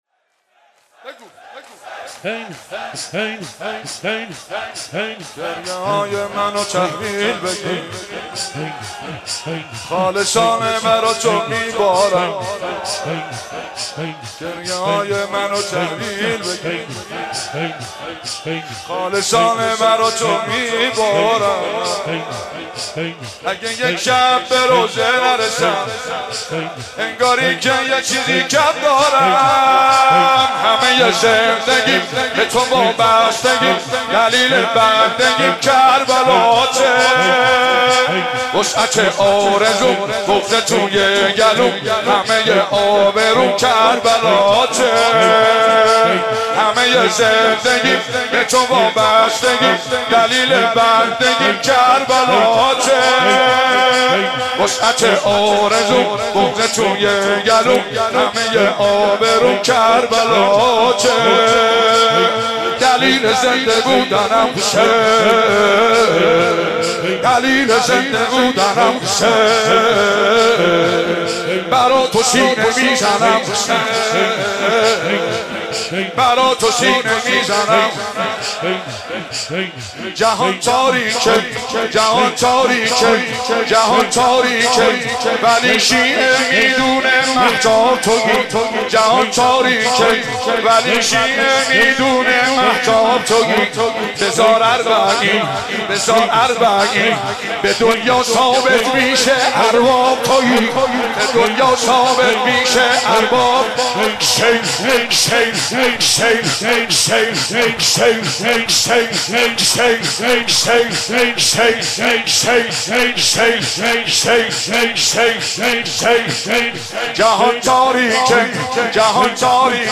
صوت مراسم شب اول محرم ۱۴۳۷ هیئت فاطمیون ذیلاً می‌آید: